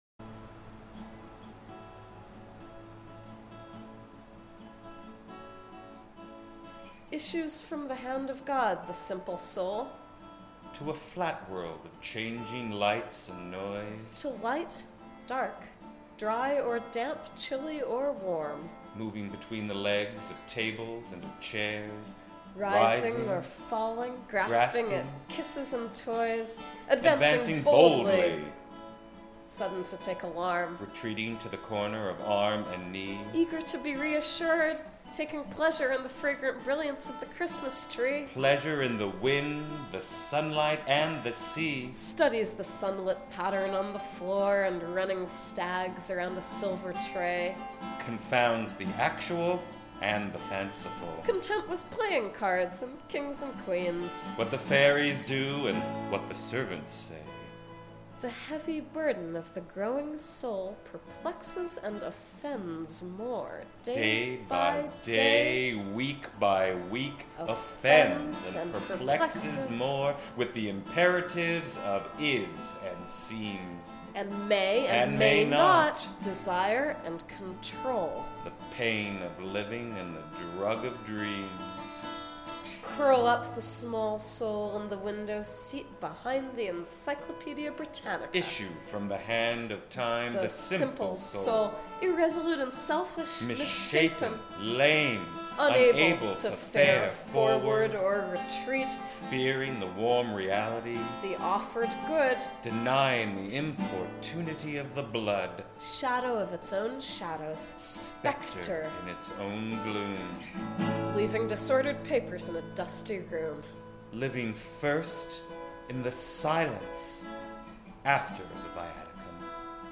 C: Bemusement. Humor. Lighthearted guitar, leading into a more desperate ending.
guitar
Recorded on Saturday, September 25, at the Blue Room at the Baltimore Free School, from 2-3:30 PM.